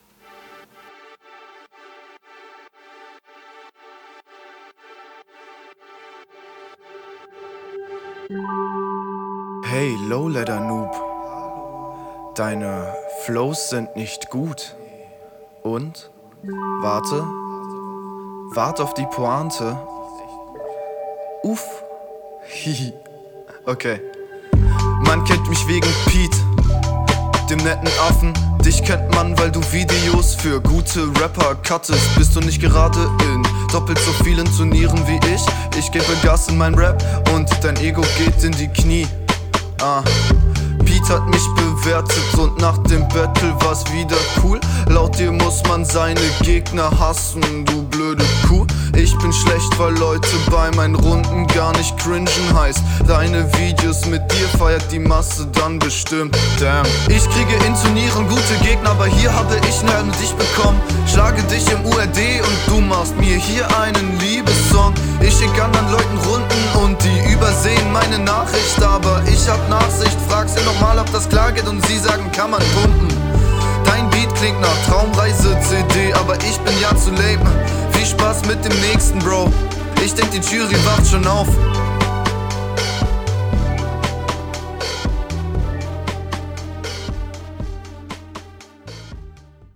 Ein eher unlustiges und unnötiges Intro ganz geil geflippt.. cooler delivert und gut gespiegelt wie …